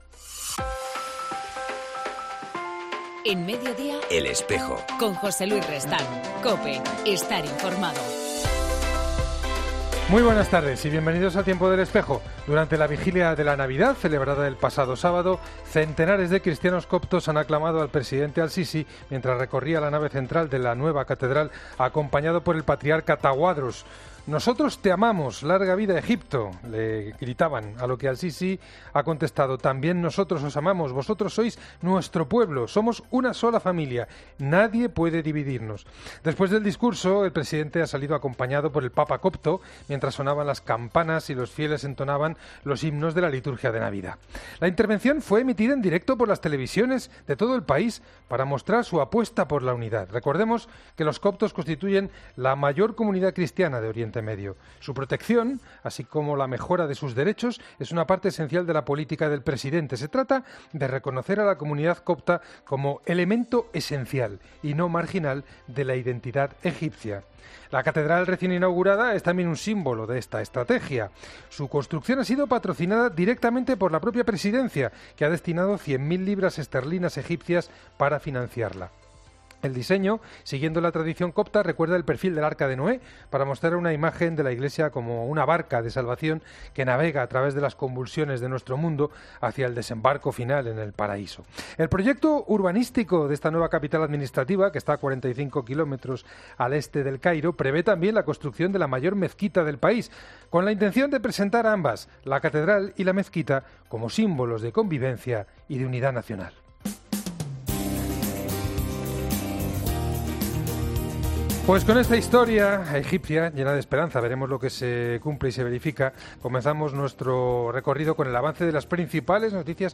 En El Espejo del 8 de enero entrevistamos a Juan Antonio Menéndez Obispo de Astorga y Presidente de la Comisión de Migraciones de la COnferencia Episcopal Española